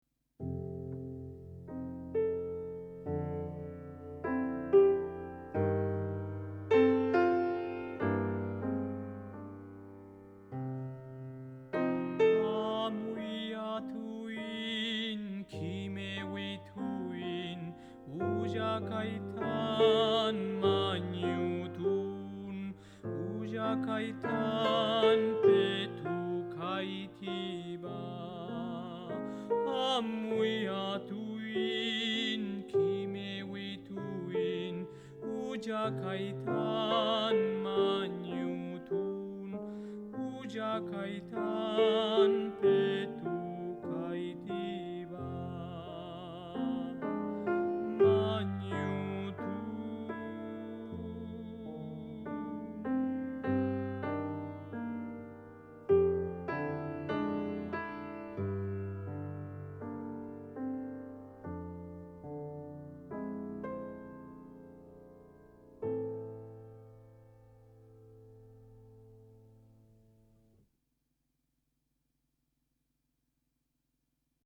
Canto
Música tradicional